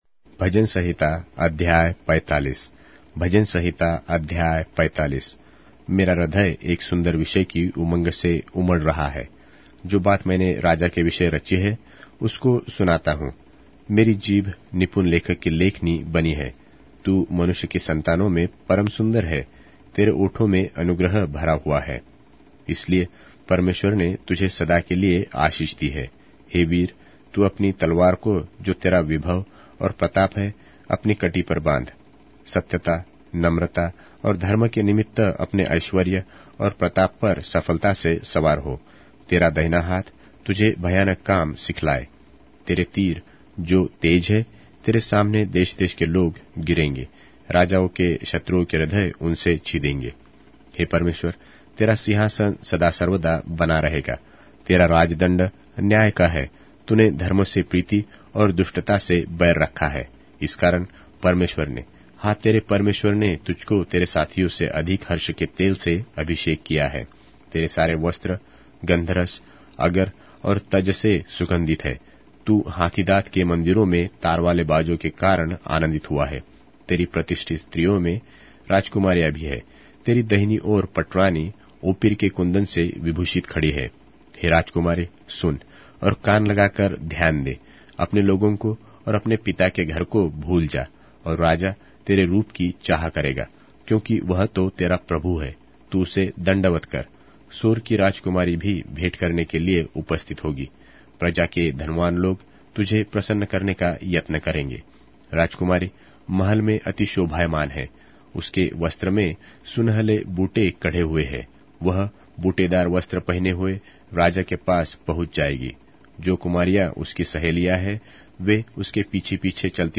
Hindi Audio Bible - Psalms 12 in Bnv bible version